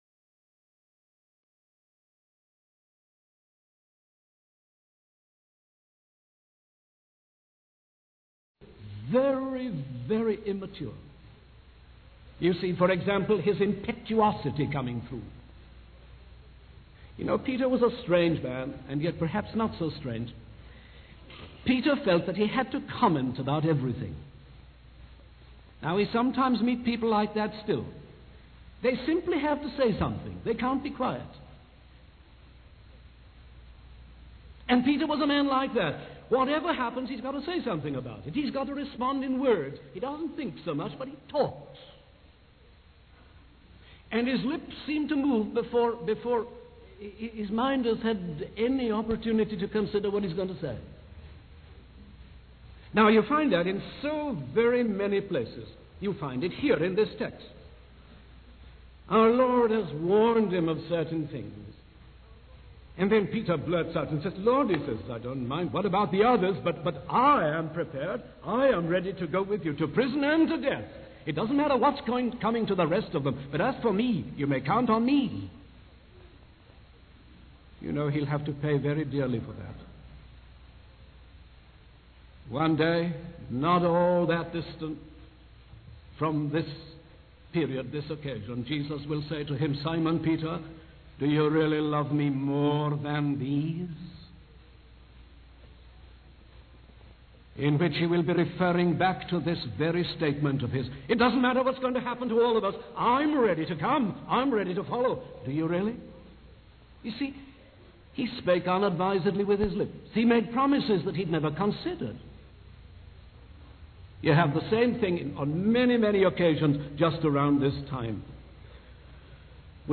The sermon also addresses the reality of spiritual warfare, explaining that Satan seeks to sift believers like wheat, but Jesus intercedes for them, ensuring their faith will not fail.